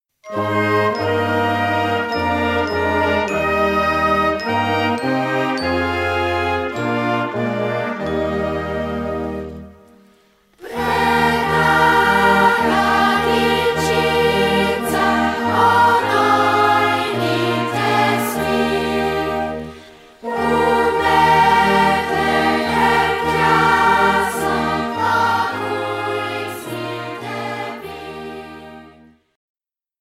Die Folkloregruppe Poljanci hat den örtlichen Jugendmusikverein und den Kirchenchor eingeladen an der Gestaltung dieser CD mitzuwirken, um die schöne und feierliche Stimmung der Wulkaprodersdorfer Weihnacht mit diesem Tonträger zu vermitteln.